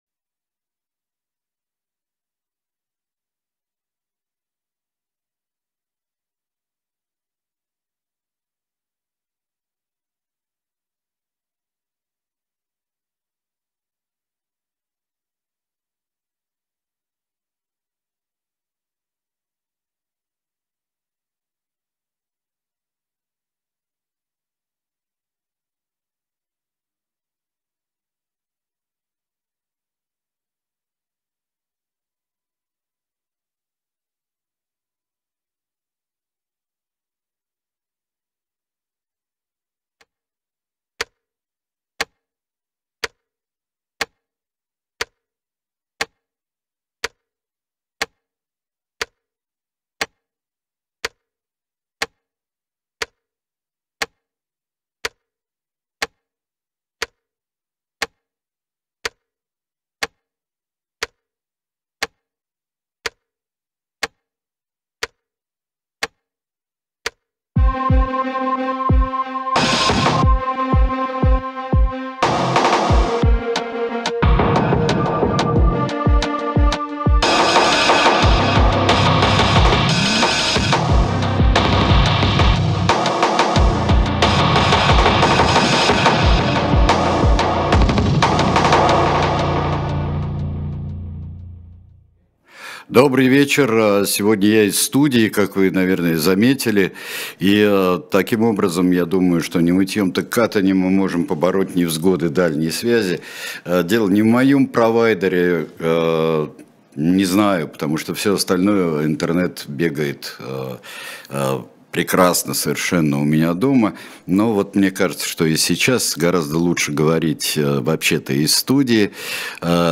Сергей Бунтман отвечает на ваши вопросы в прямом эфире